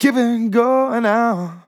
Keeping going out Vocal Sample
Categories: Vocals Tags: DISCO VIBES, dry, english, going, Keeping, LYRICS, male, out, sample
man-disco-vocal-fills-120BPM-Fm-12.wav